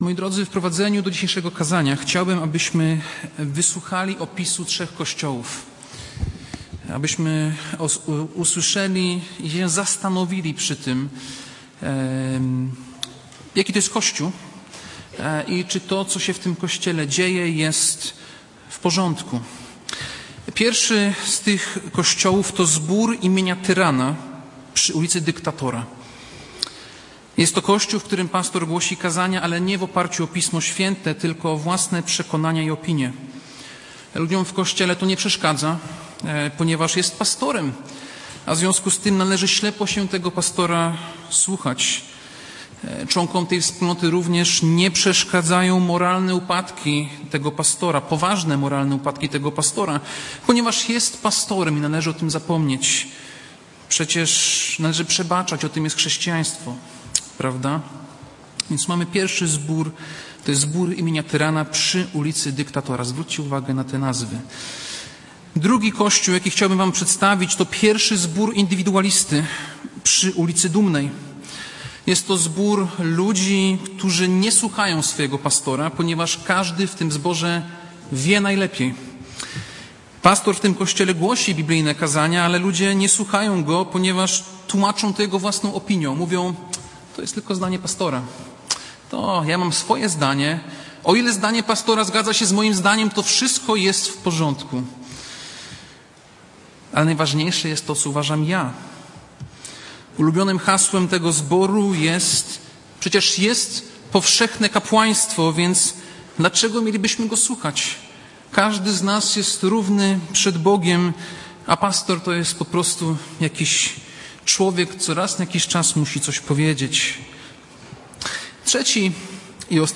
Passage: List do Hebrajczyków 13, 17 Kazanie